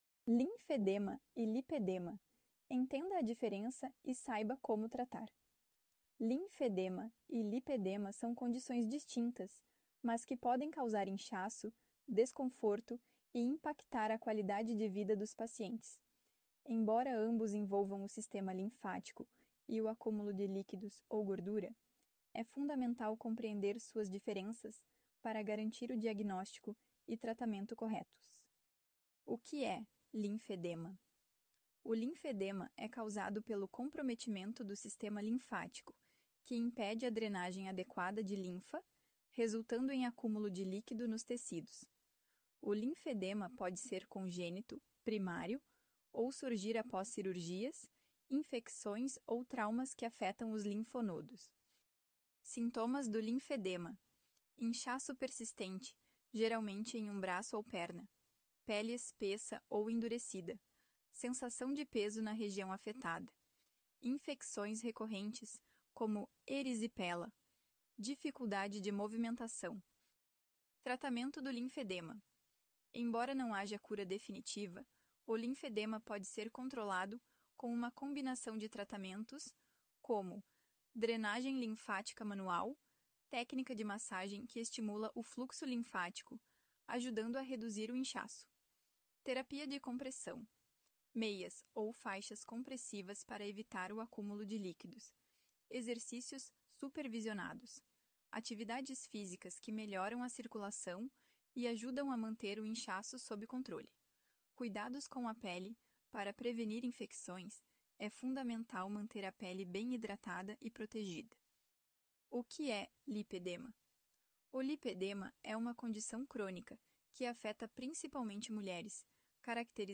Vana - Assistente vascular